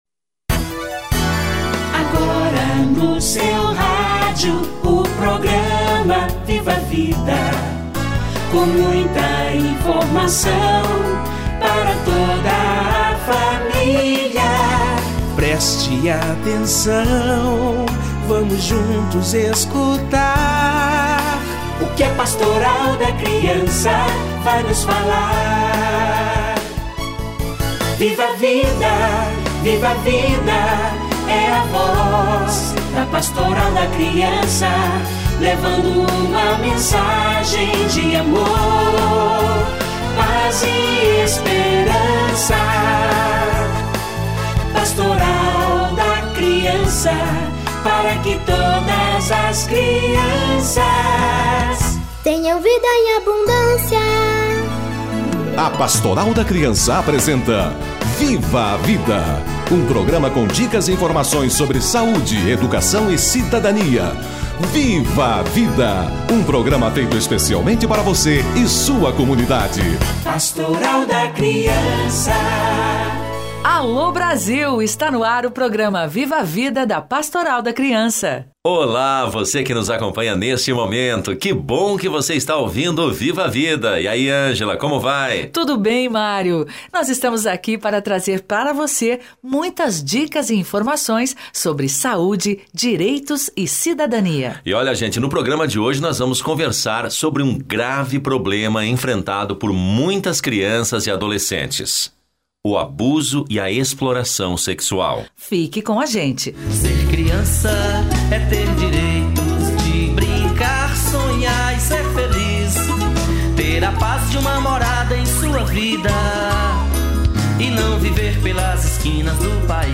Abuso sexual/exploração contra a criança - Entrevista